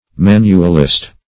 Search Result for " manualist" : The Collaborative International Dictionary of English v.0.48: Manualist \Man"u*al*ist\, n. One who works with the hands; an artificer.